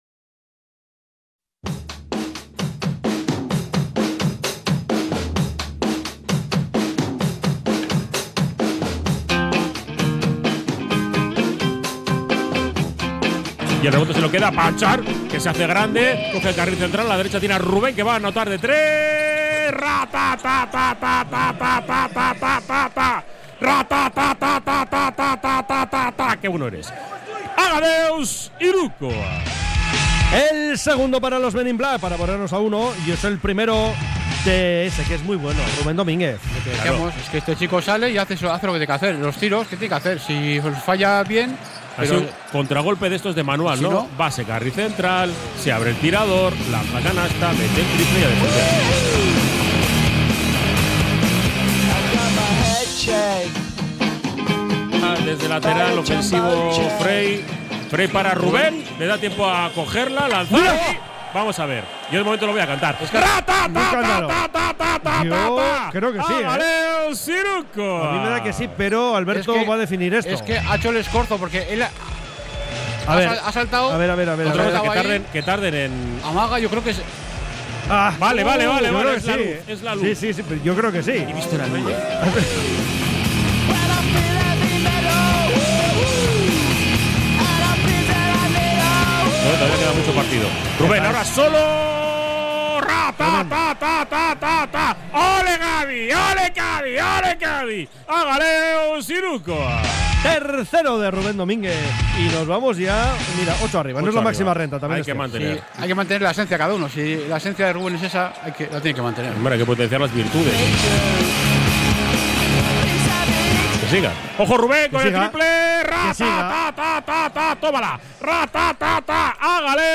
Así narramos los ocho hirukoas de Rubén Domínguez